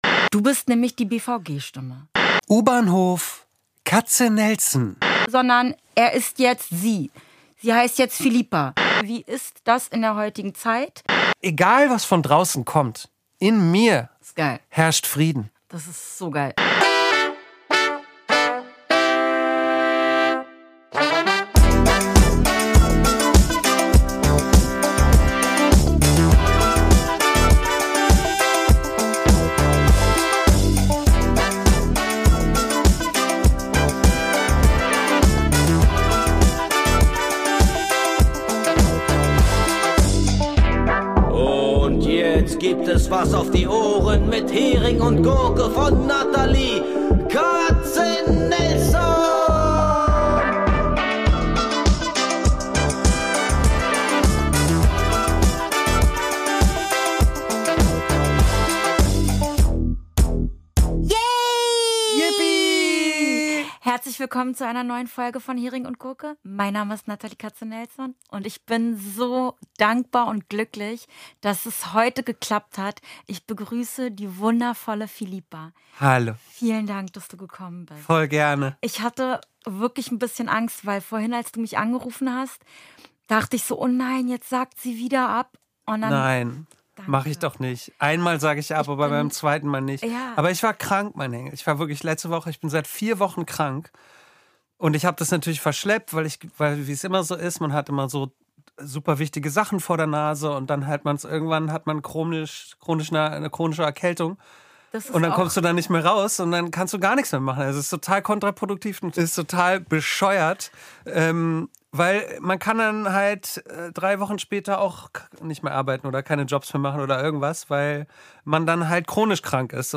Ein inspirierendes Gespräch über Identität, Mut und die Kraft, man selbst zu sein.